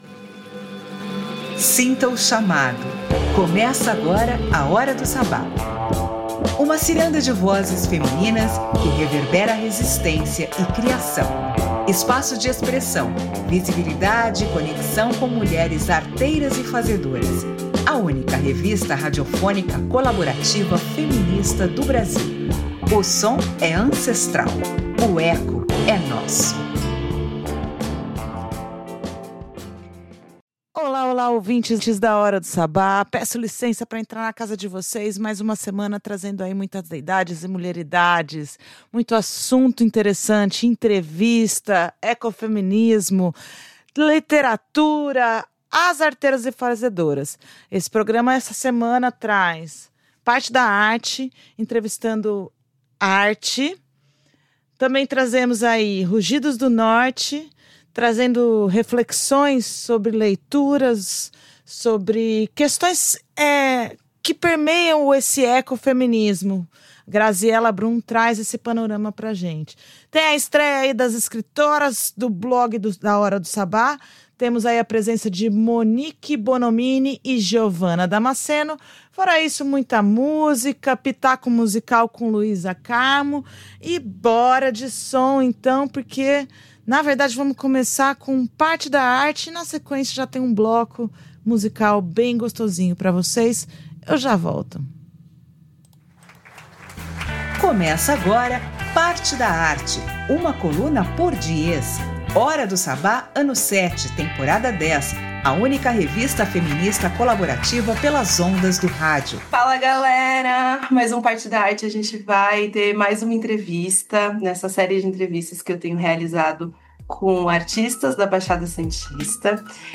Escute agora o novo episódio da Hora do Sabbat, com entrevista, estreia de quadro novo, setlist de arrepiar e muita bruxaria sonora.